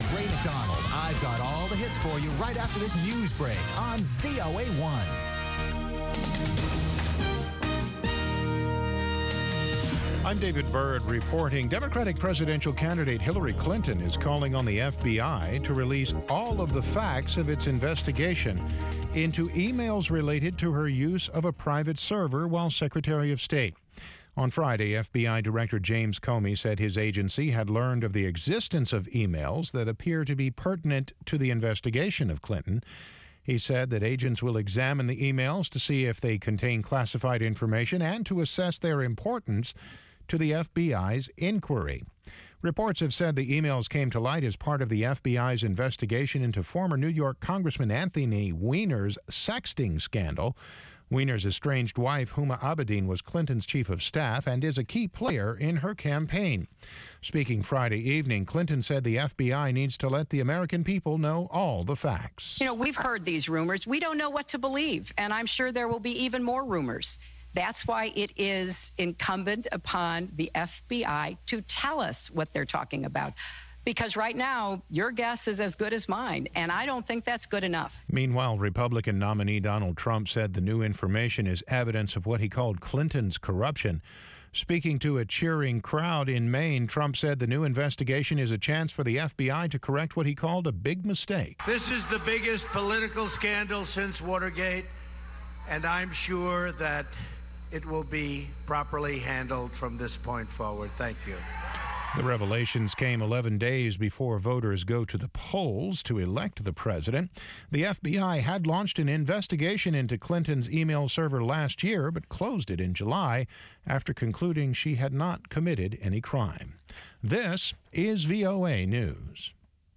Una discusión de 30 minutos sobre los temas noticiosos de la semana con diplomáticos, funcionarios de gobiernos y expertos.